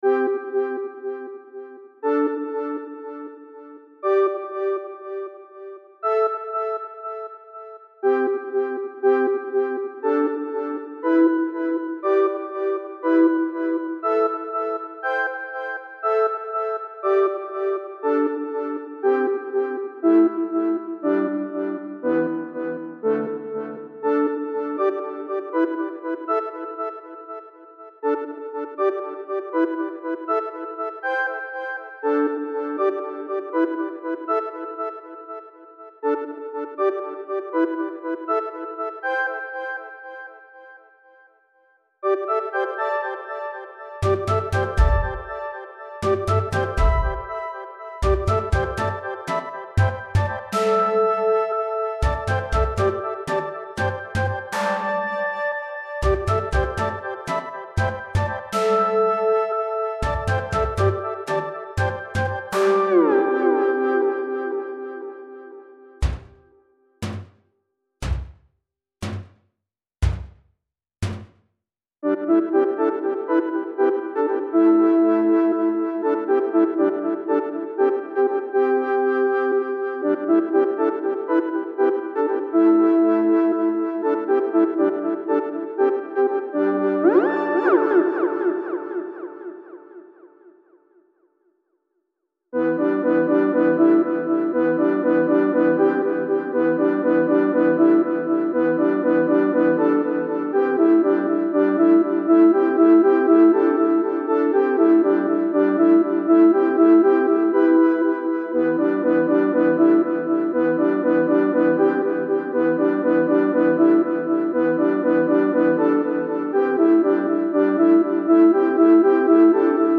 Two instruments is all you need! 19/10/2024